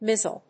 発音記号
• / mízl(米国英語)
• / ˈmɪz.əl(英国英語)